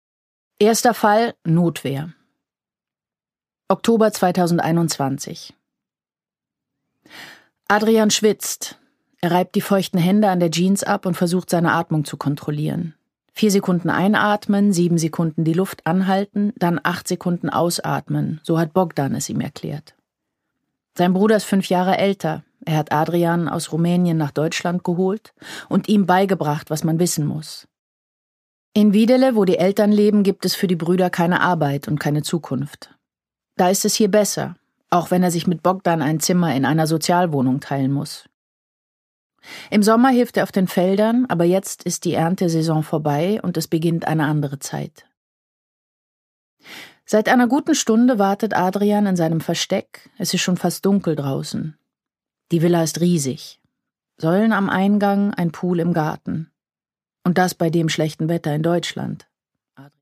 Produkttyp: Hörbuch-Download
Gelesen von: Nina Kunzendorf